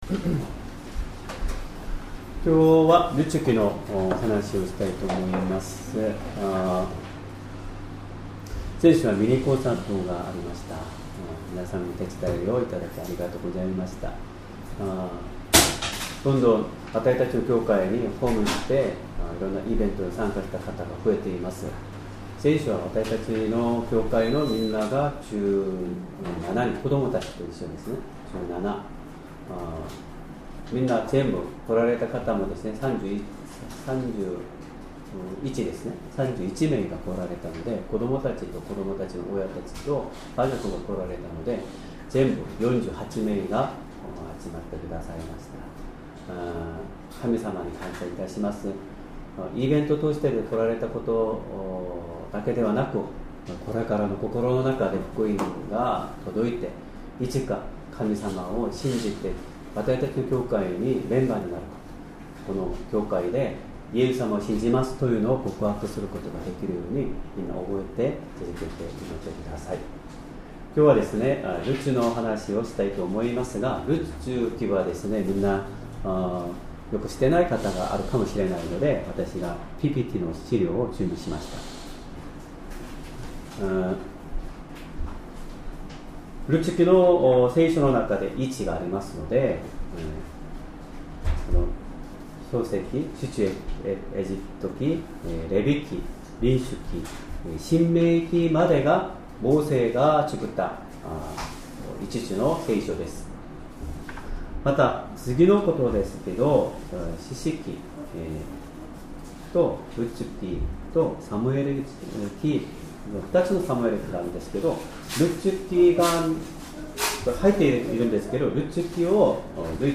Sermon
2025年6月22日 主日礼拝 説教 「ルツの信仰 」 聖書 ルツ記 1章15-17節 1:15 ナオミは言った。